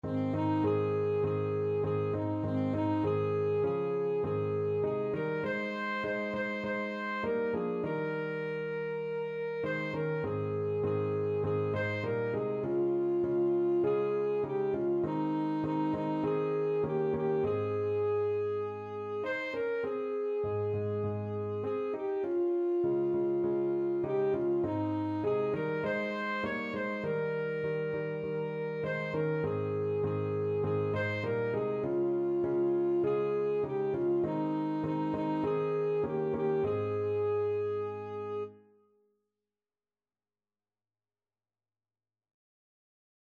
Christian
Alto Saxophone
4/4 (View more 4/4 Music)